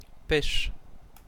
Ääntäminen
US : IPA : [piːtʃ]